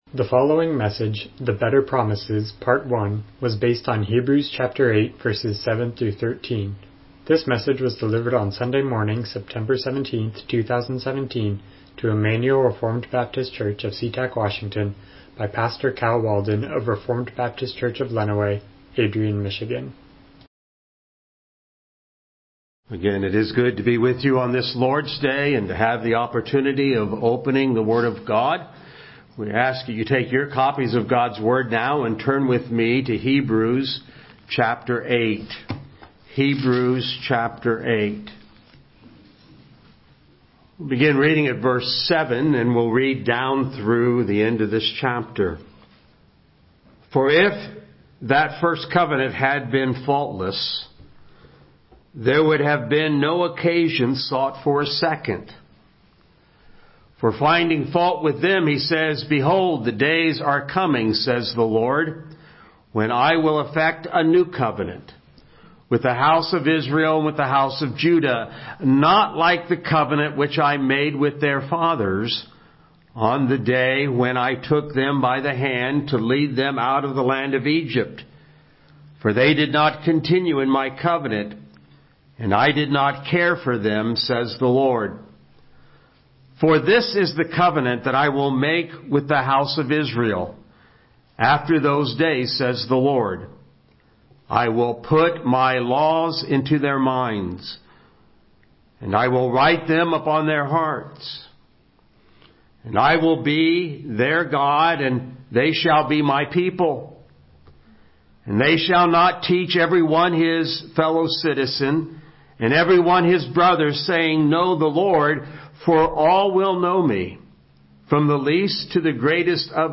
Miscellaneous Passage: Hebrews 8:7-13 Service Type: Morning Worship « Church Membership